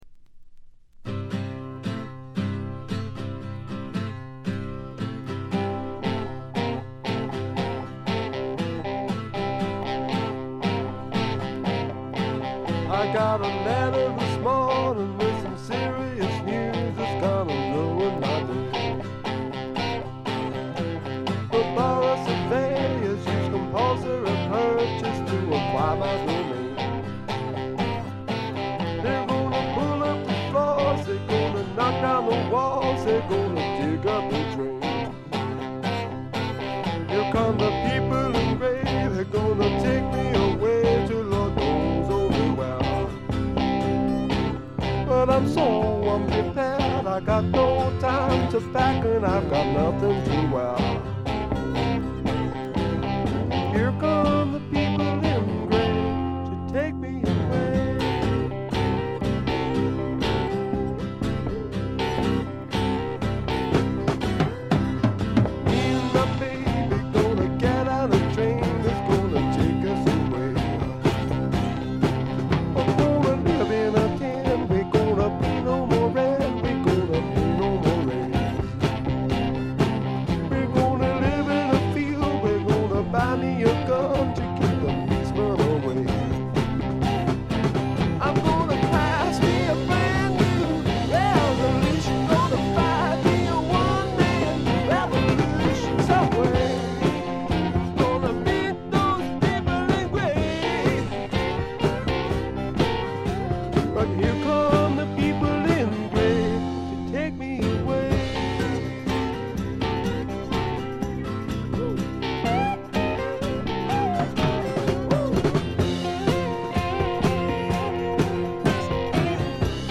これ以外は軽微なバックグラウンドノイズに散発的なプツ音少し。
試聴曲は現品からの取り込み音源です。